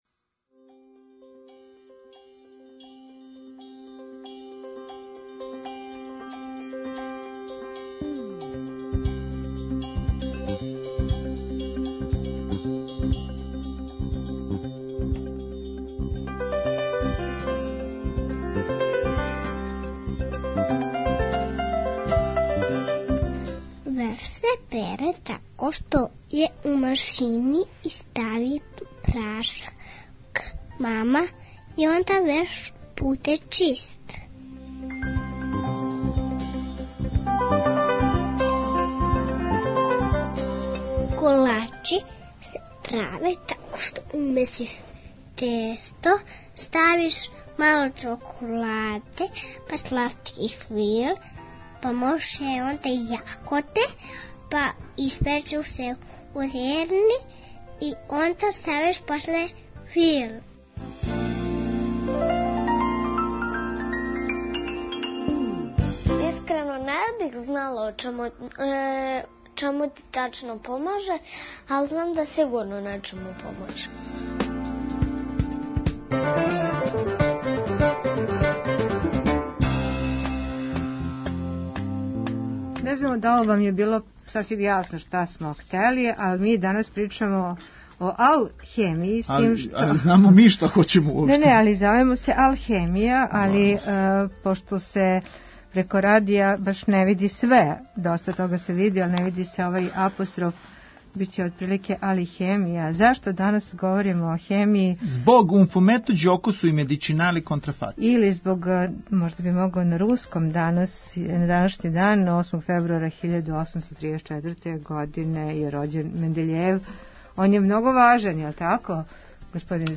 То питамо као и увек најмлађе, уметнике - сликаре